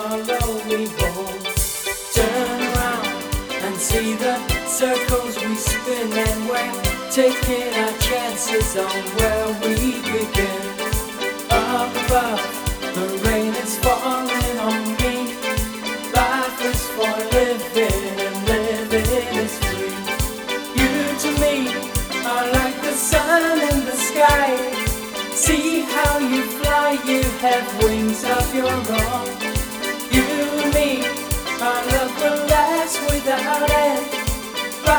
Жанр: Рок / Фолк